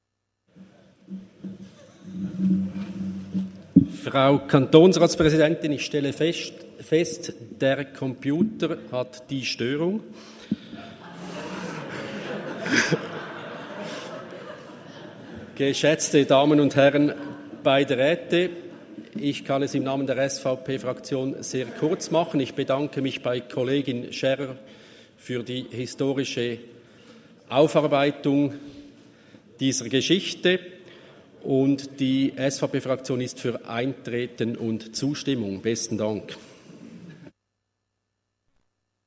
Session des Kantonsrates vom 27. bis 29. November 2023, Wintersession
27.11.2023Wortmeldung